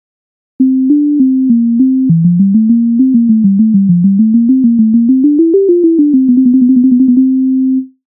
MIDI файл завантажено в тональності F-dur